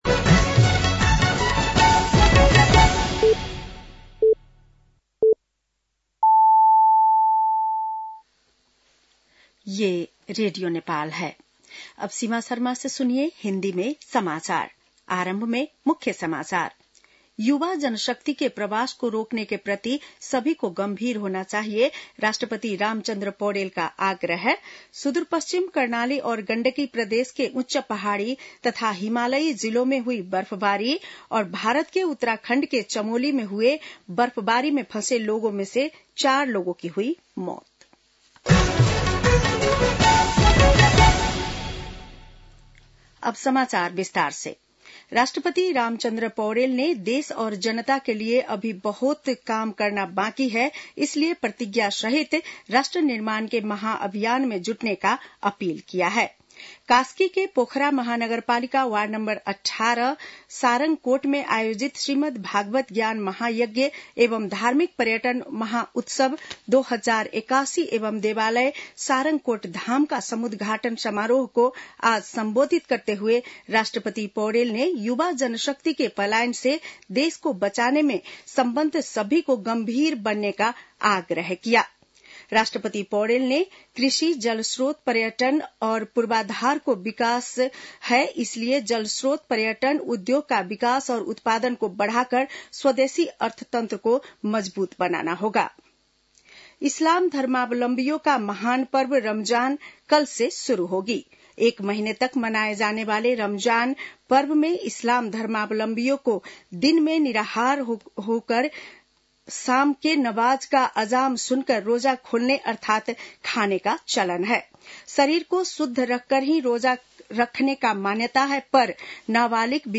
बेलुकी १० बजेको हिन्दी समाचार : १८ फागुन , २०८१